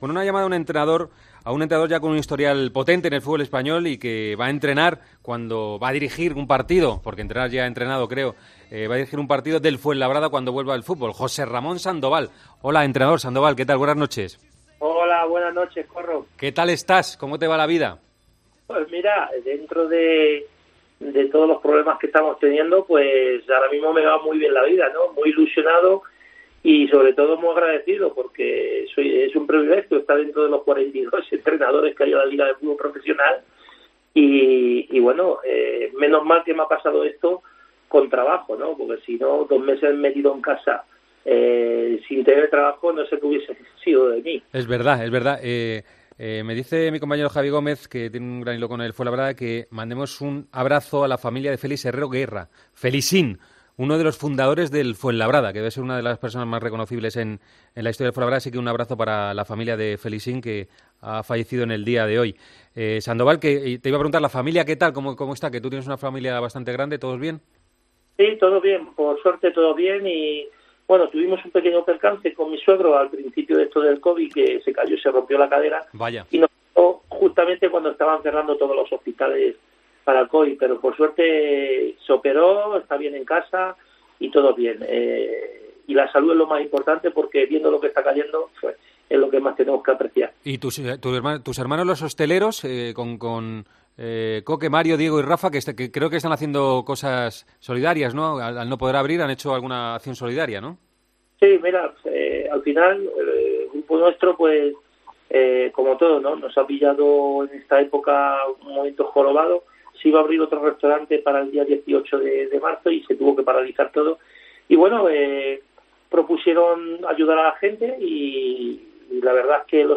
AUDIO: Hablamos con el técnico del Fuenlabrada sobre el regreso del fútbol tras el coronavirus.